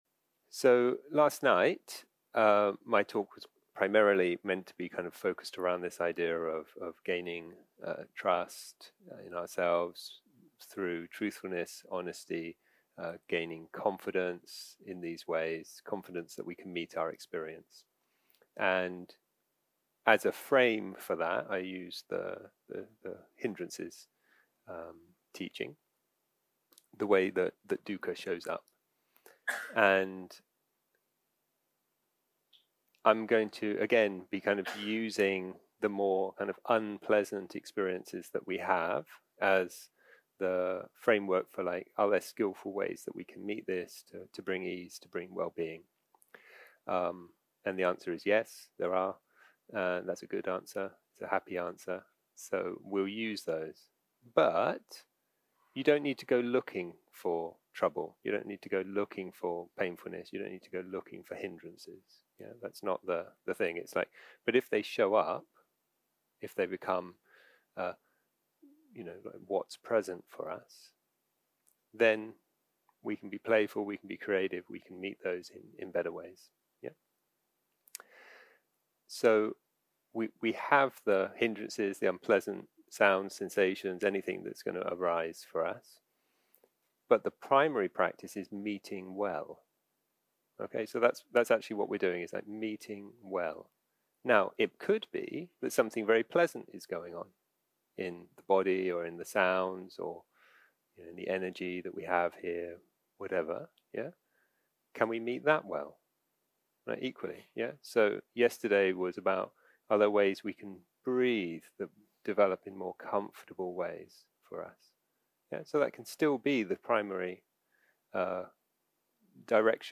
יום 4 - הקלטה 8 - בוקר - הנחיות למדיטציה - Meeting Well
Guided meditation שפת ההקלטה